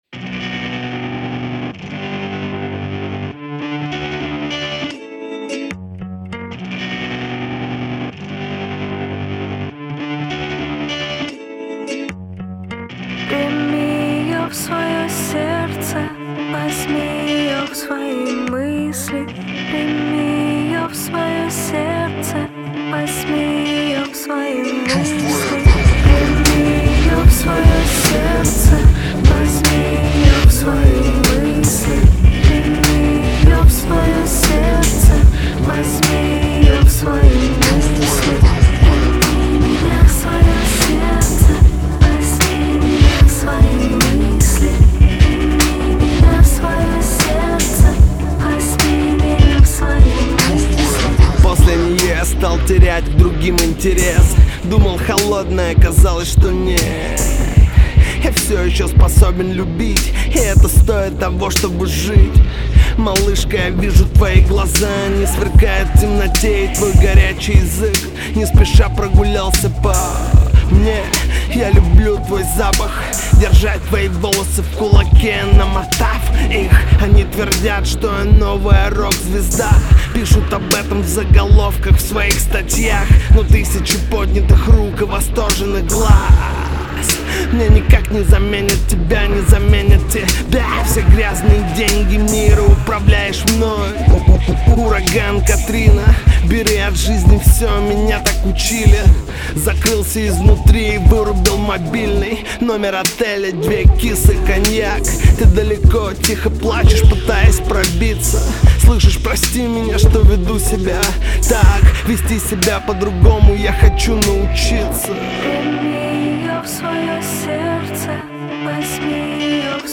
Назад в (рэп)...